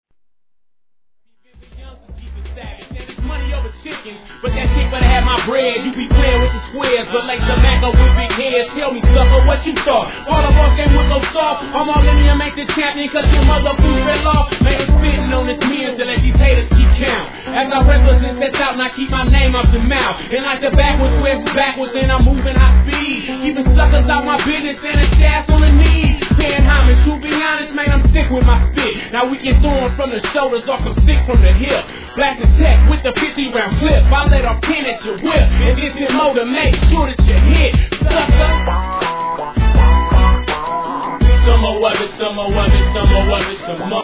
G-RAP/WEST COAST/SOUTH
ポワ〜ワ〜ンシンセWORKにやられます。